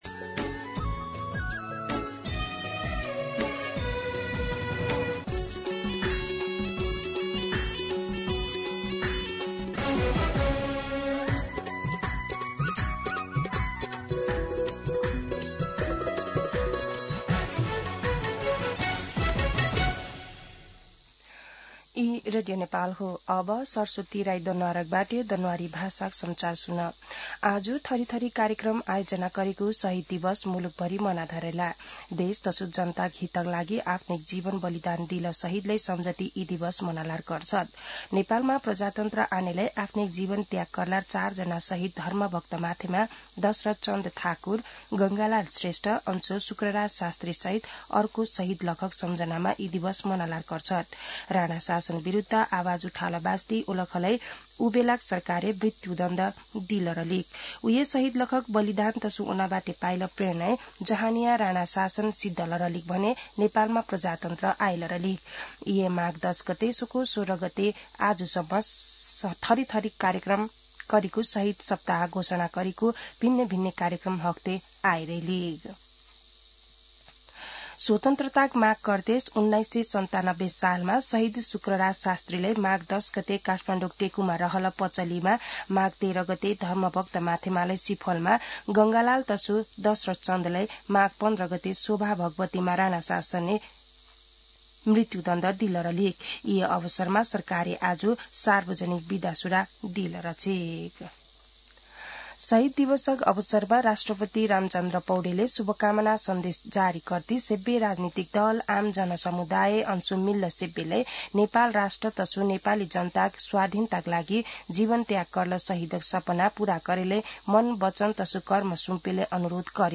दनुवार भाषामा समाचार : १६ माघ , २०८२